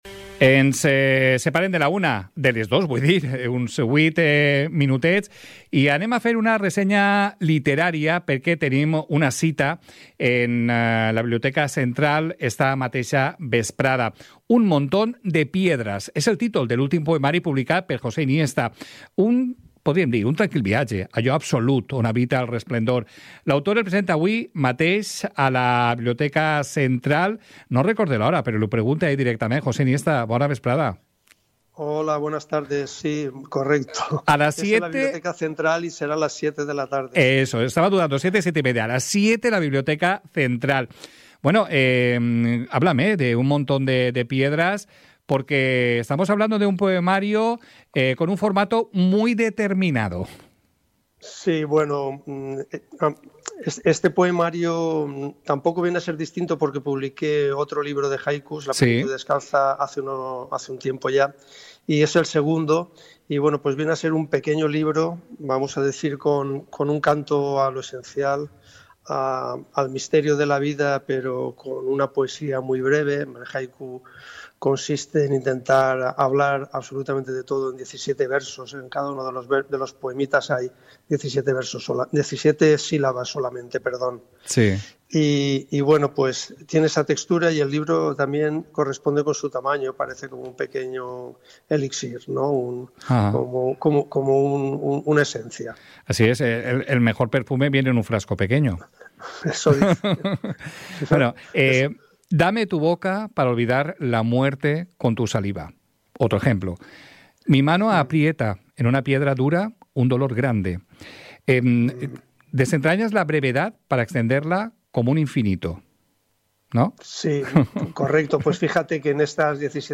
Entrevista en el programa Hoy por Hoy Gandia al poeta